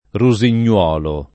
vai all'elenco alfabetico delle voci ingrandisci il carattere 100% rimpicciolisci il carattere stampa invia tramite posta elettronica codividi su Facebook rusignolo [ ru @ in’n’ 0 lo ] (lett. rusignuolo [ ru @ in’n’ U0 lo ]) → rosignolo